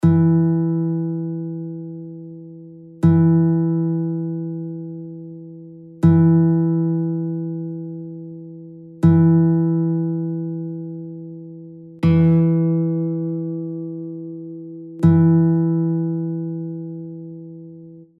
Стрій Ля (A),
4-та струна – мі, E (mp3):
Bandurka_A-strij_4_E3.mp3